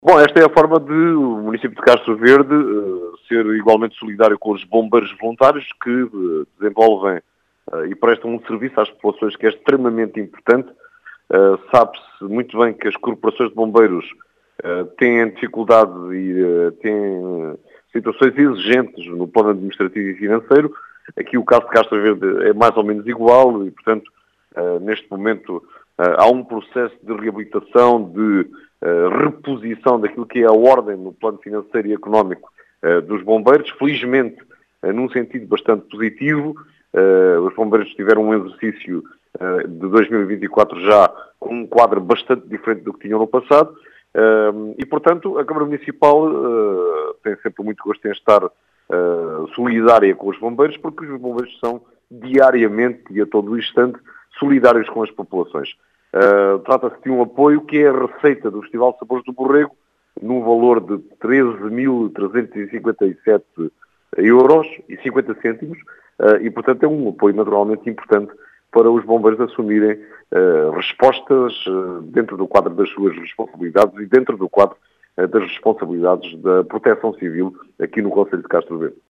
Uma forma da autarquia ser “solidária” com os bombeiros de Castro Verde, segundo explicou António José Brito, presidente da Câmara de Castro Verde, que fala num “apoio importante” para a corporação.